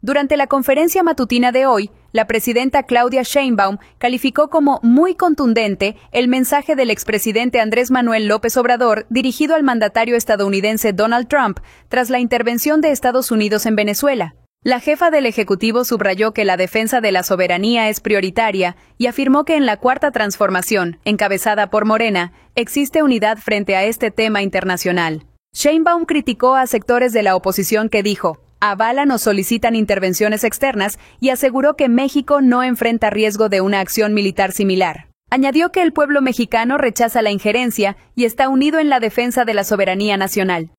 Durante La Mañanera, la presidenta Claudia Sheinbaum calificó como “muy contundente” el mensaje del expresidente Andrés Manuel López Obrador dirigido al mandatario estadounidense Donald Trump, tras la intervención de Estados Unidos en Venezuela.